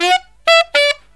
63SAXMD 05-R.wav